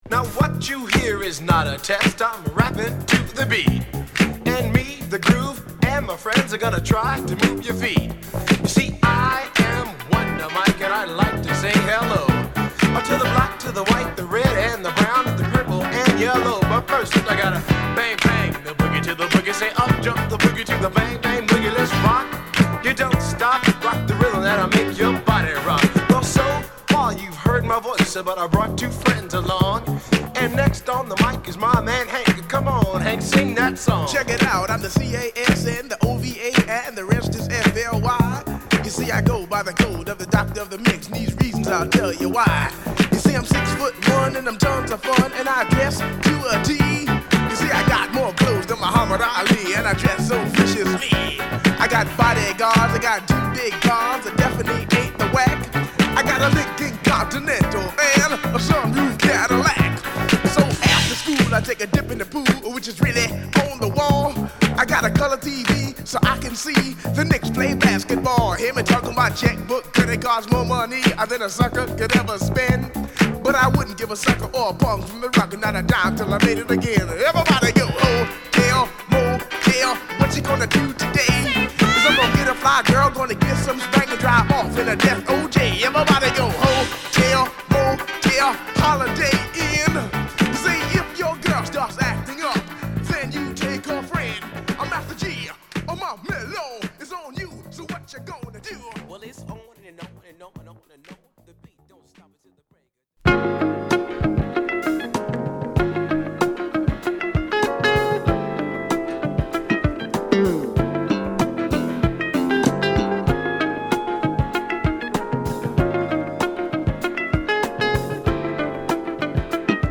Home > Old School Rap